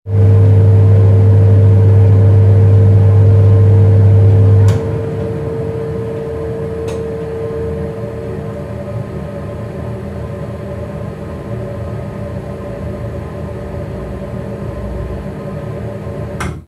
Caldera de gas: apagado
Sonidos: Hogar